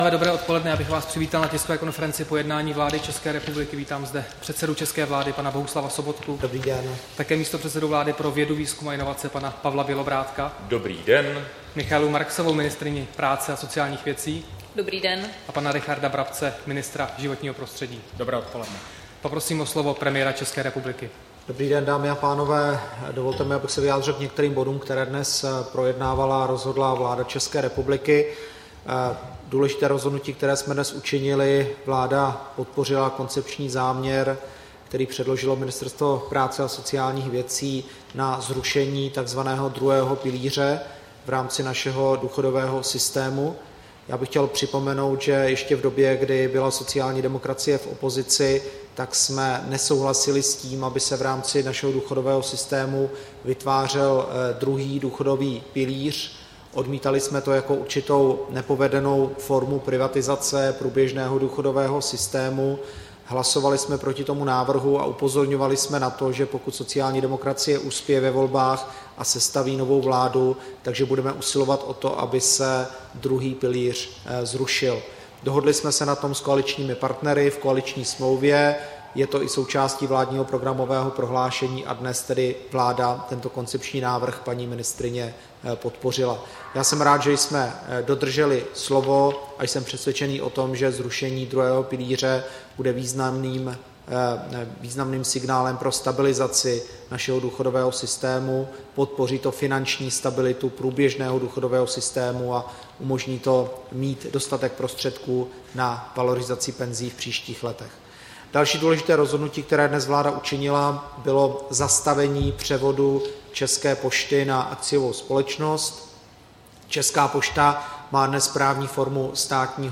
Tisková konference po jednání vlády, 12. listopadu 2014